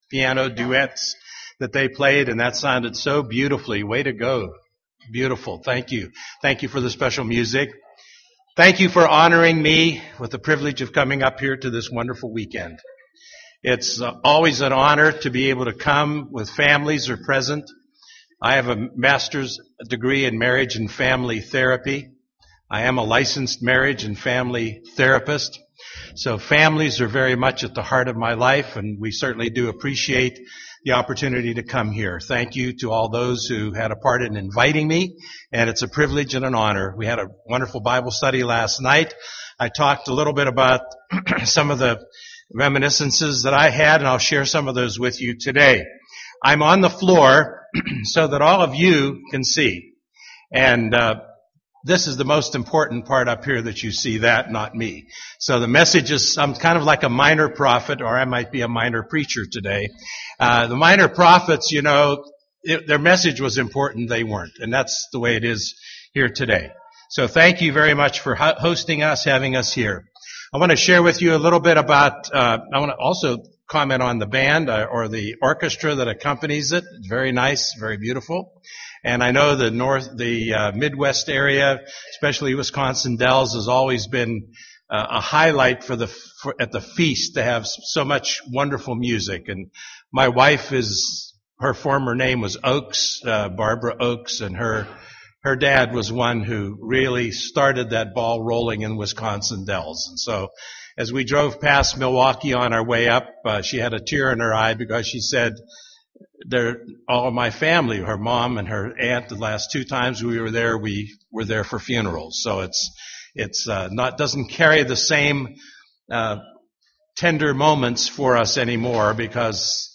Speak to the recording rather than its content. This message was the sermon for the Twin Cities Families for God Weekend, April 1-3, 2011